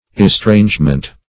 Estrangement \Es*trange"ment\, n. [Cf. OF. estrangement.]